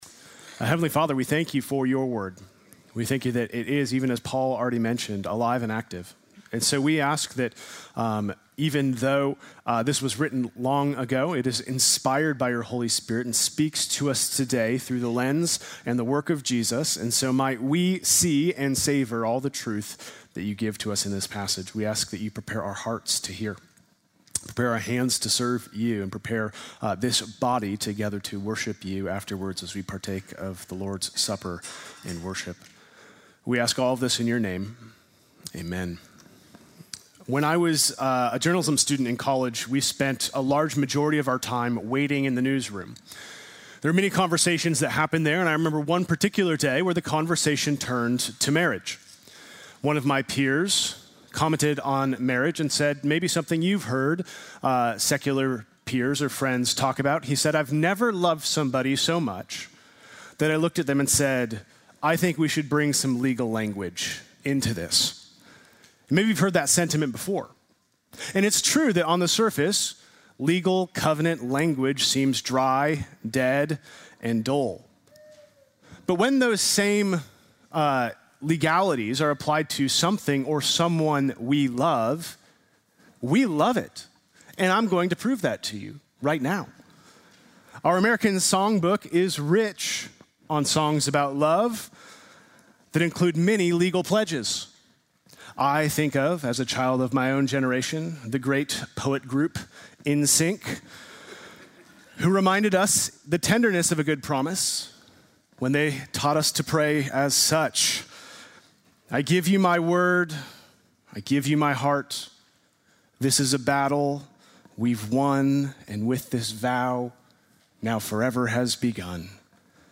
Sunday morning message March 1